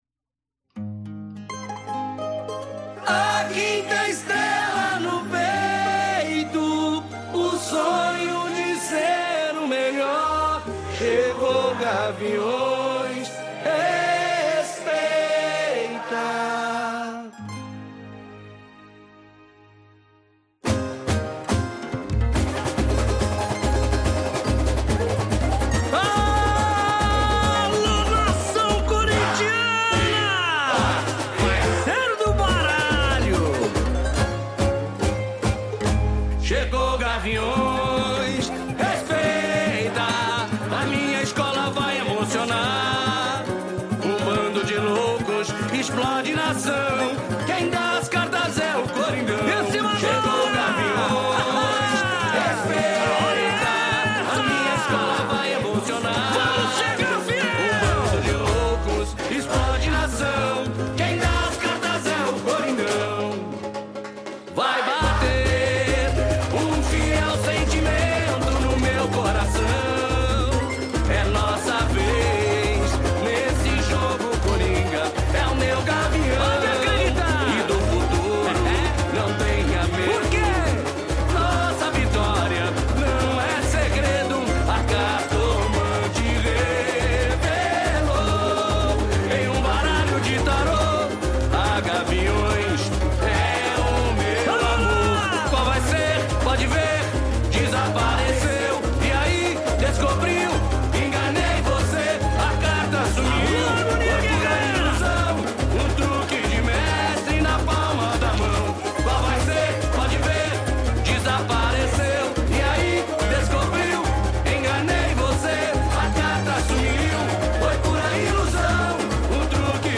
Interprete: